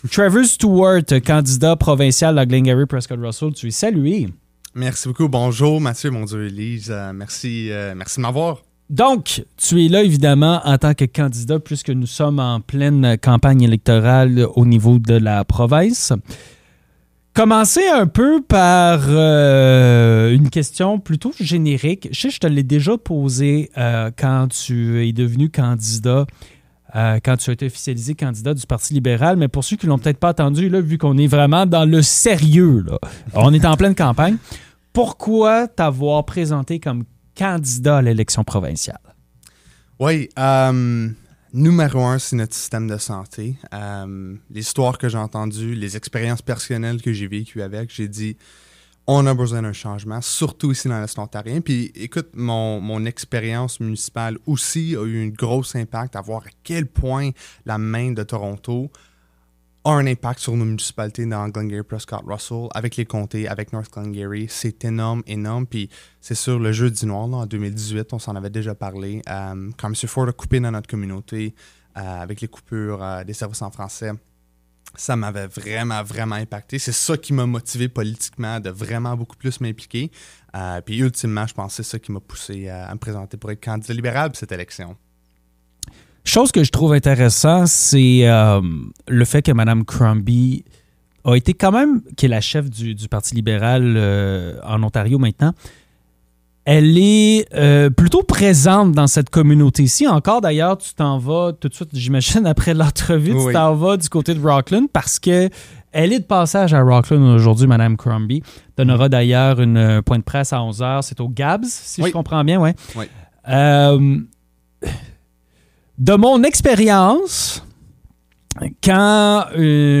Élections provinciales 2025 - Entrevue